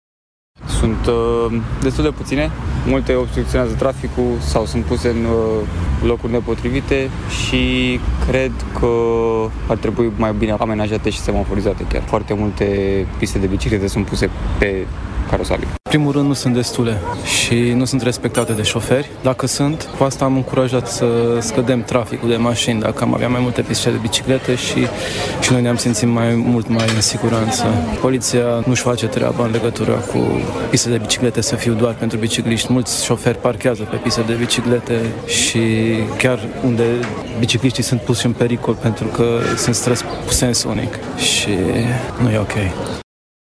vox-brasov.mp3